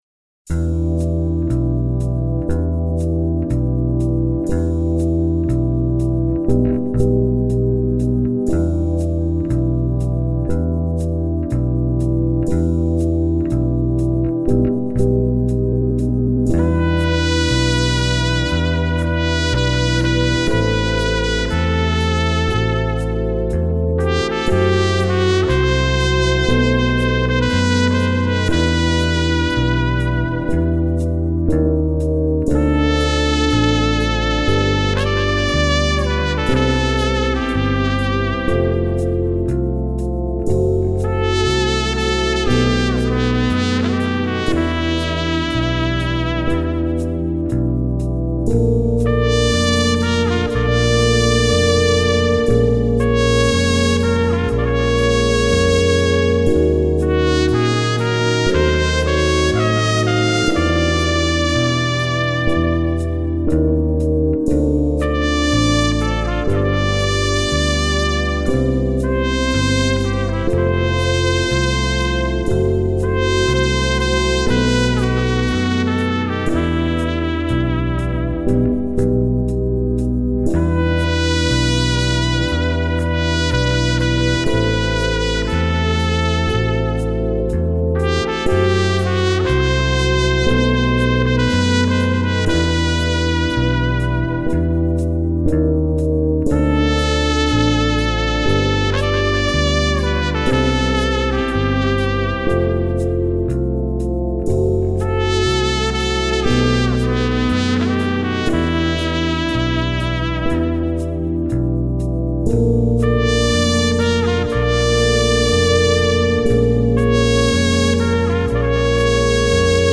latin1.mp3